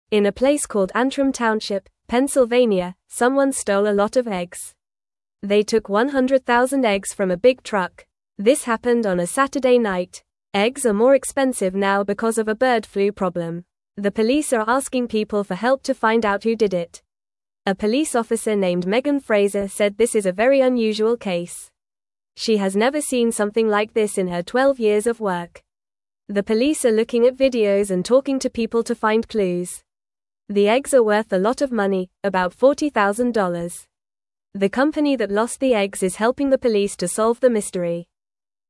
Normal